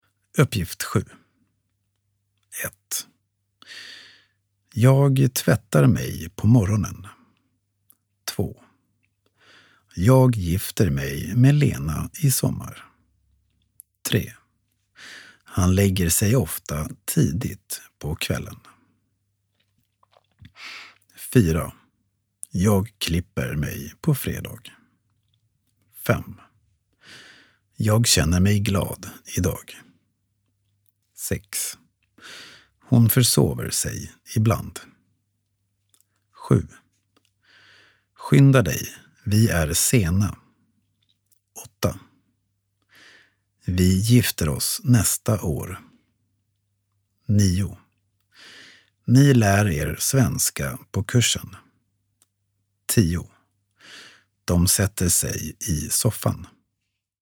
Uppgift-7-Langsam-1.mp3